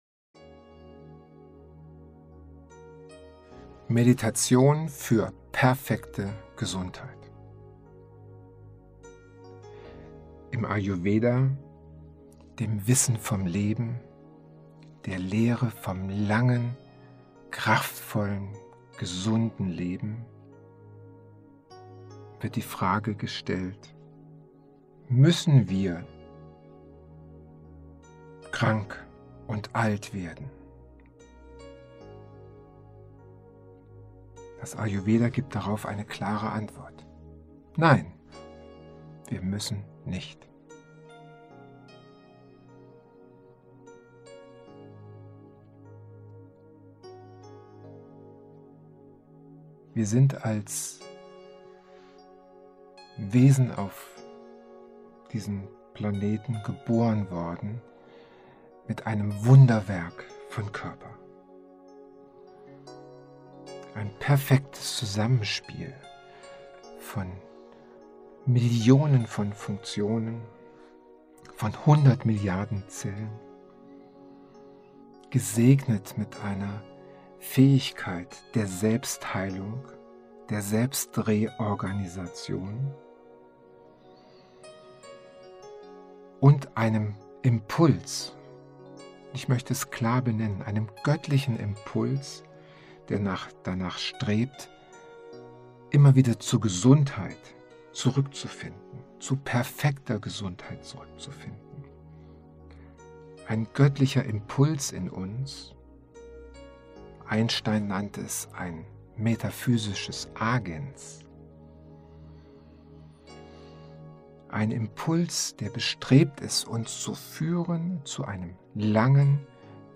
Gesundheitsmeditation.mp3